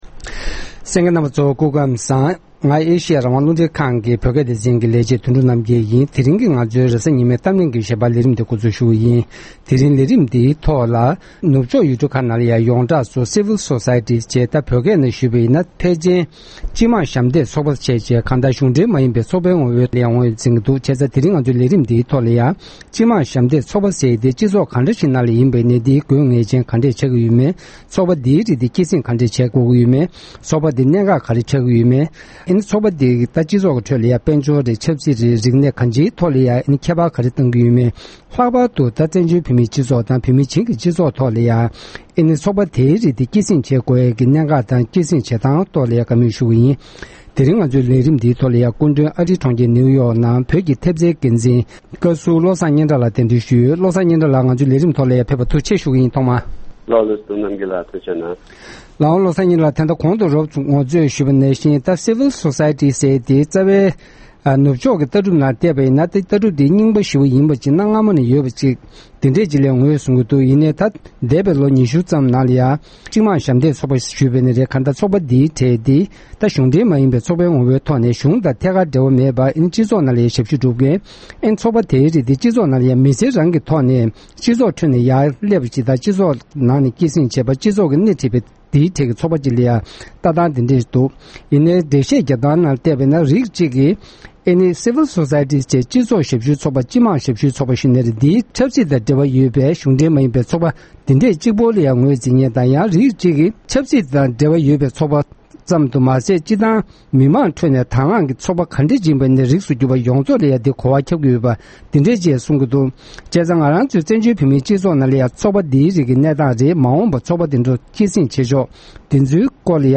༄༅། །ཐེངས་འདིའི་གཏམ་གླེང་གི་ལེ་ཚན་ནང་། དྲང་བདེན་དང་ཆ་སྙོམས་ལྡན་པའི་སྤྱི་ཚོགས་བསྐྲུན་ཐབས་སུ་ Civil Society འམ་ སྤྱི་དམངས་ཞབས་འདེགས་ཚོགས་པའི་གནད་འགག་དང་། དེ་ལྟར་སྐྱེད་སྲིང་དང་གསོ་སྐྱོང་བྱ་ཐབས་དེ་བཞིན་བཙན་བྱོལ་བོད་མིའི་སྤྱི་ཚོགས་ཁྲོད་དེ་འདྲའི་གནས་བབས་སྐོར་བཀའ་ཟུར་བློ་བཟང་སྙན་གྲགས་མཆོག་དང་ལྷན་དུ་བགྲོ་གླེང་ཞུས་པ་གསན་རོགས་གནང་༎